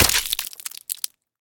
claw3.ogg